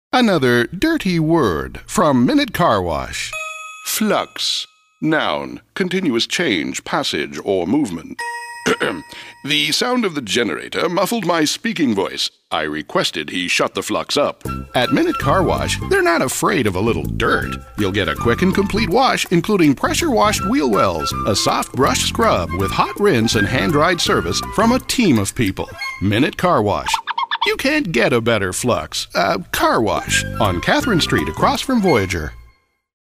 Character Comedy Characterization Radio V/O